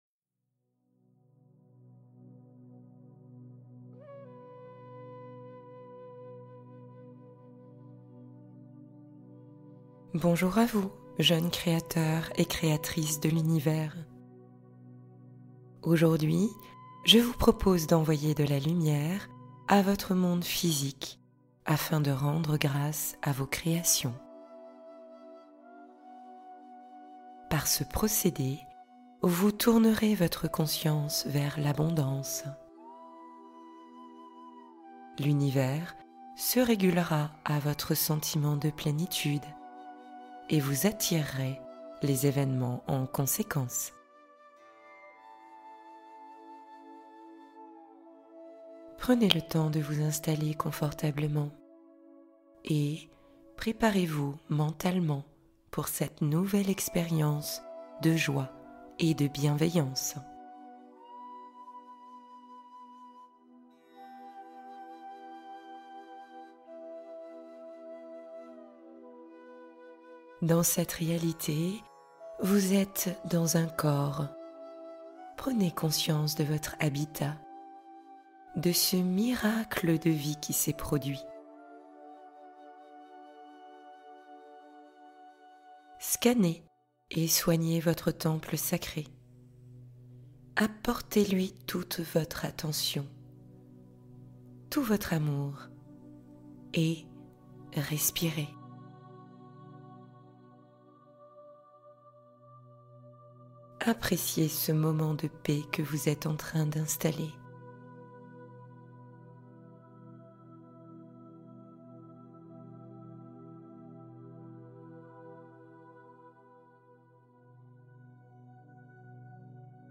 Chemin de lumière : traversée guidée vers la clarté intérieure